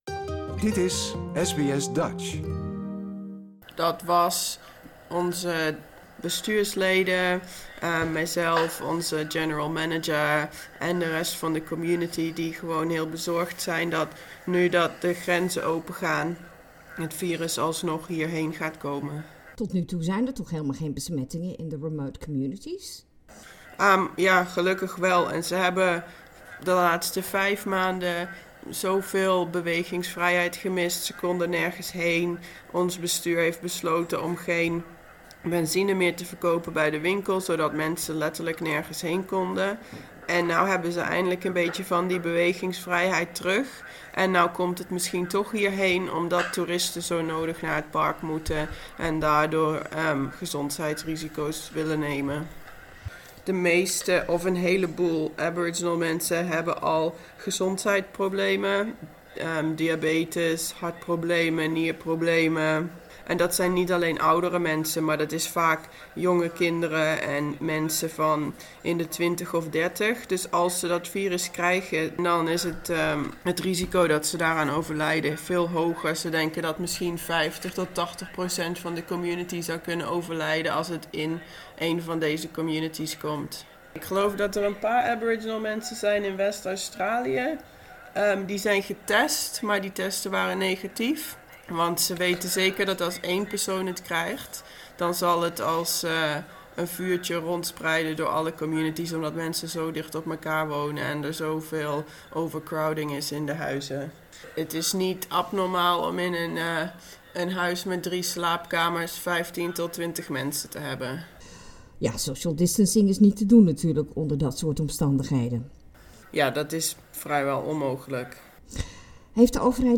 Ik vroeg haar wie dat allemaal waren die de oproep gedaan hebben en we praten ook over hoe zij daar terecht is gekomen.